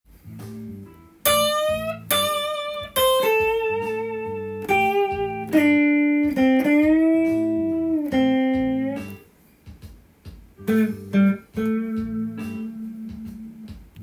ブルース系だとこのような雰囲気です。
blues.utau_.m4a